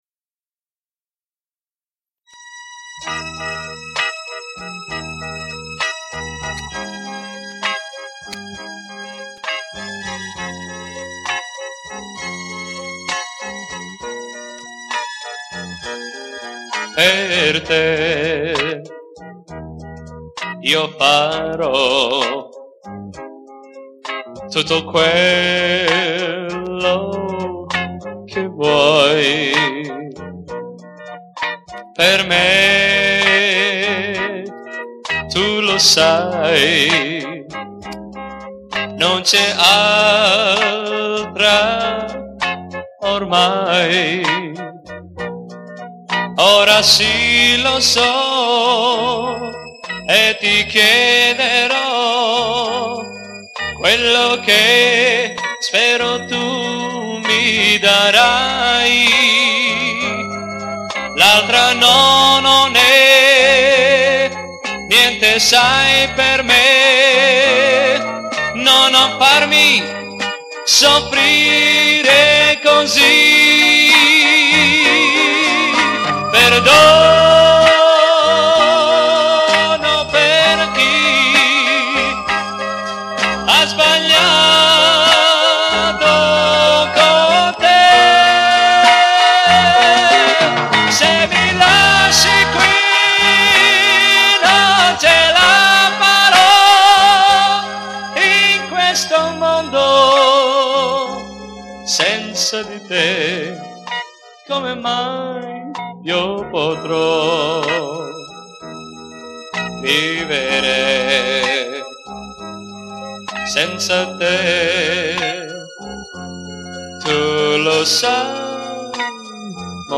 Piano Accordion, Organ, Strings & Electric Piano
Electric & Acoustic Guitars
Electric Bass
Drums
Brass Section
BACKGROUND VOCALS
Recorded at Riversound Recordings, Sydney